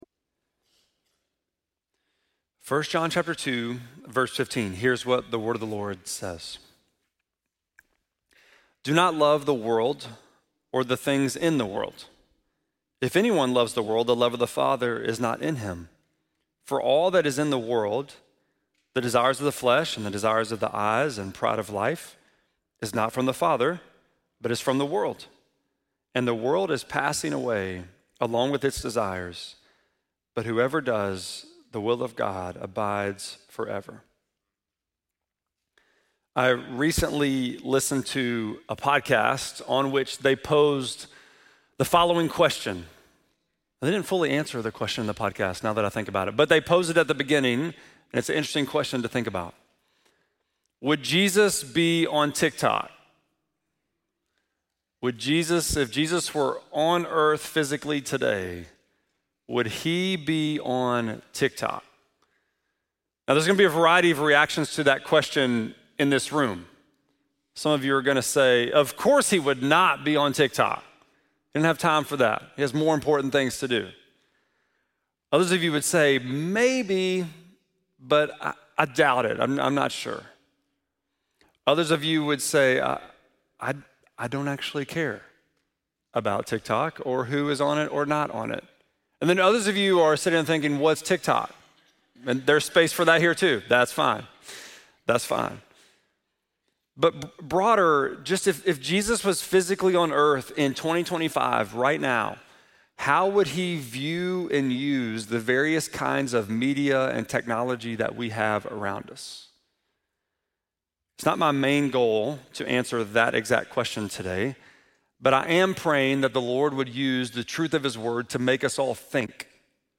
6.22-sermon.mp3